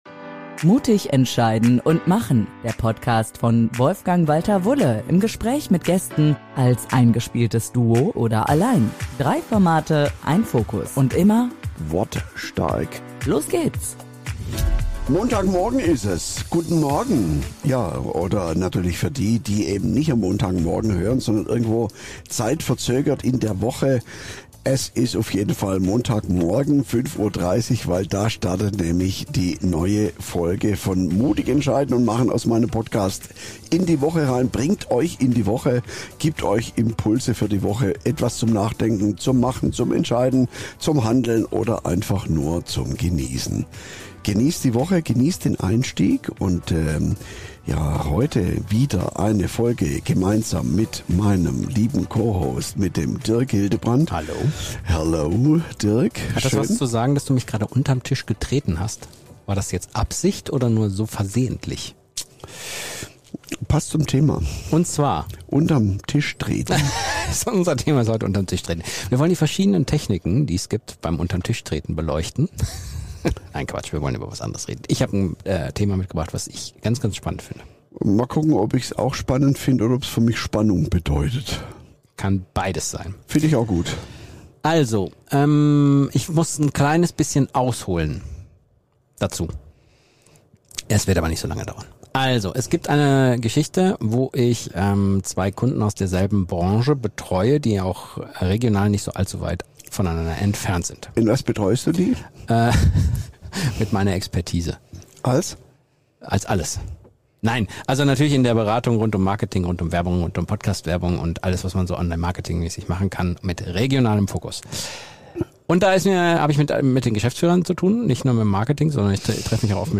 Die beiden beleuchten, wie Angst vor Konkurrenz entsteht, was unser Emotionssystem damit zu tun hat und warum Kooperation häufig nicht nur doppelte, sondern vielfache Wirkung entfalten kann. Mit vielen Praxisbeispielen, Humor und klaren Impulsen lädt diese Folge dazu ein, alte Denkmuster zu hinterfragen und mutig neue Wege im Miteinander zu gehen – für mehr Wirkung, mehr Reichweite und mehr Zukunftsfähigkeit.